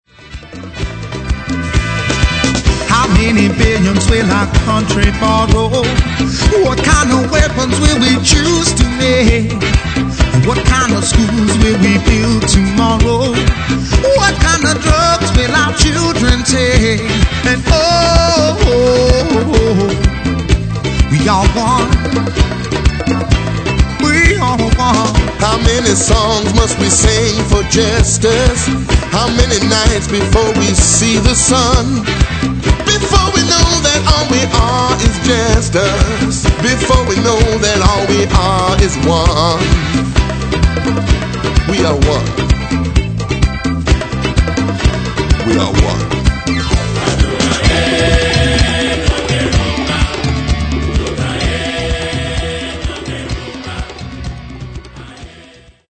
African rhythms are fused with modern elements
Vocals, Accordian and Rubboard
Djembe and Vocals